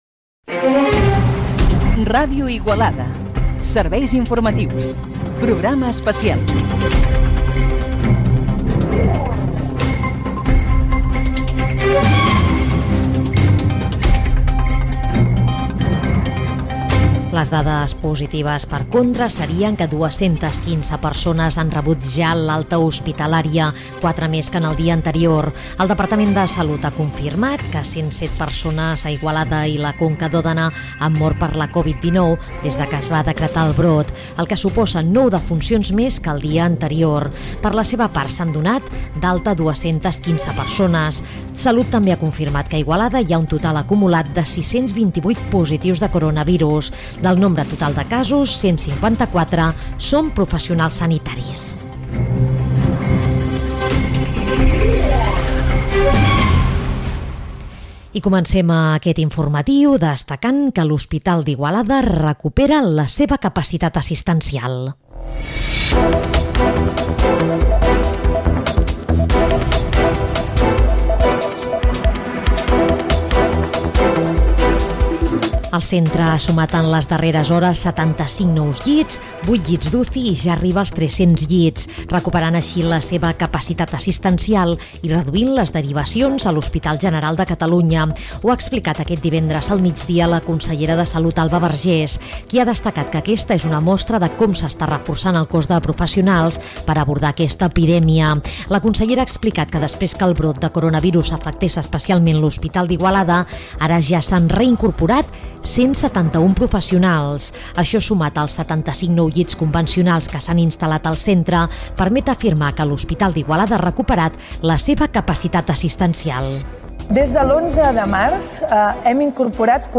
Careta del programa. Informació de la situació de la pandèmia de la Covid-19 a Igualada. Declaracions de la consellera de Sanitat de la Generalitat Alba Vergés: reincorporats 171 sanitaris a l'Hospital d'Igualada i fetes 42 noves contractacions des de l'inici del brot